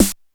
Snares
SIX_SNR.WAV